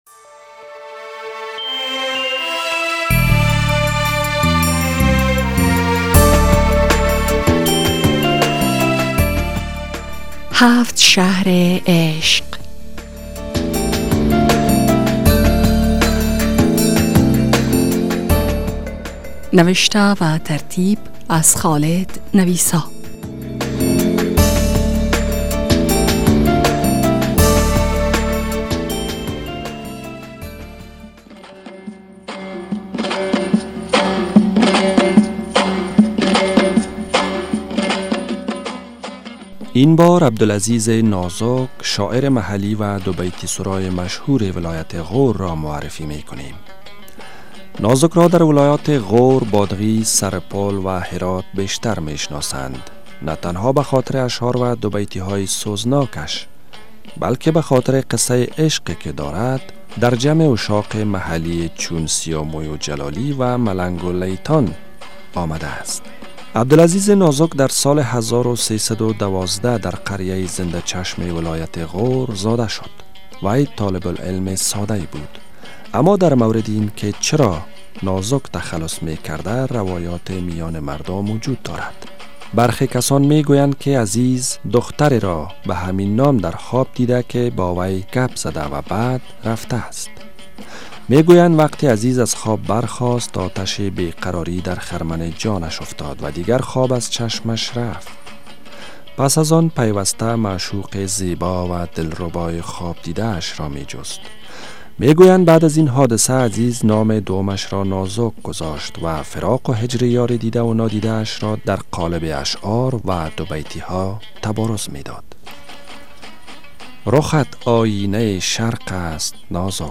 عشق در دوبیتی و دوتار